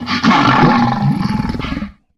Cri de Félicanis dans Pokémon HOME.